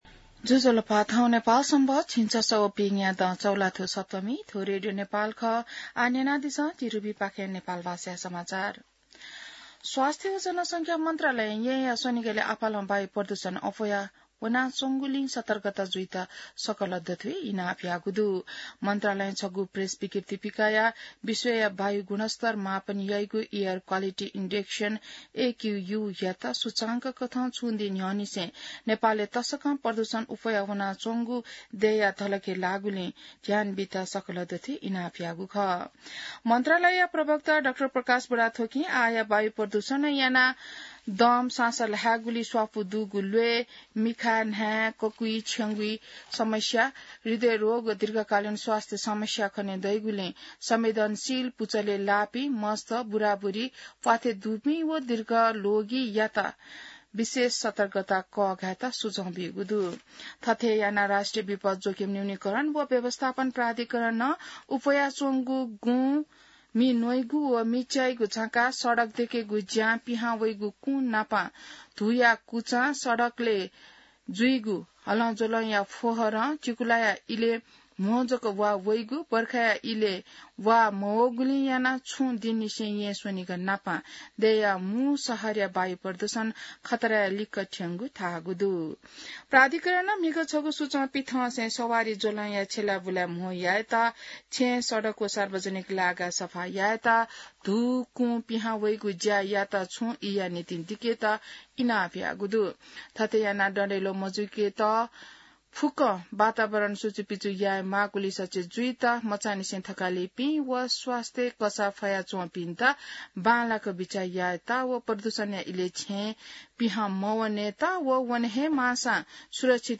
नेपाल भाषामा समाचार : २२ चैत , २०८१